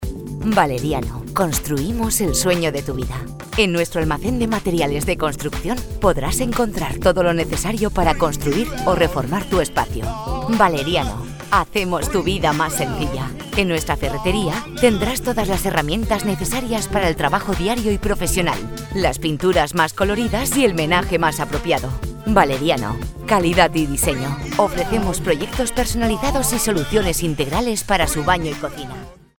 Grabación de publicidad para Valeriano: Construimos el sueño de tu vida.